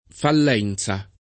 fallenza [ fall $ n Z a ]